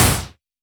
Index of /musicradar/retro-drum-machine-samples/Drums Hits/WEM Copicat
RDM_Copicat_SY1-Snr01.wav